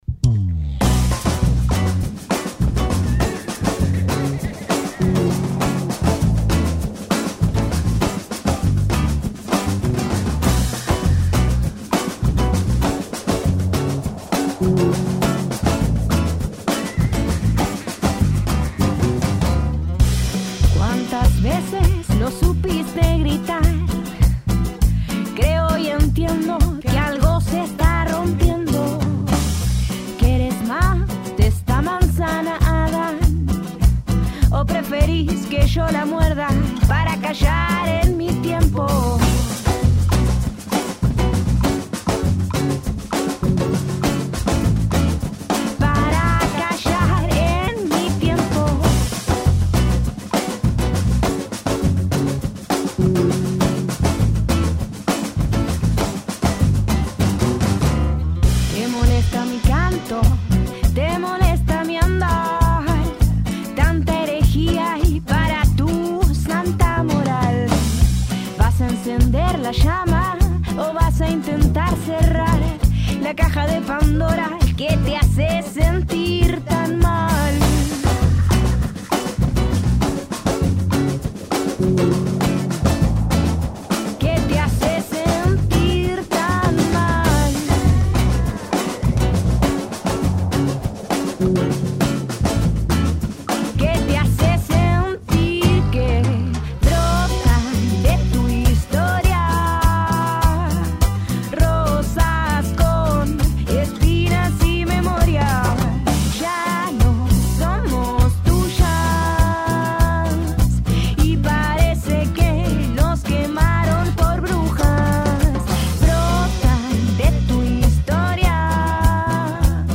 Charla y acústico